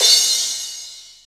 Index of /90_sSampleCDs/Sound & Vision - Gigapack I CD 1 (Roland)/CYM_CRASH mono/CYM_Crash mono
CYM CRA12.wav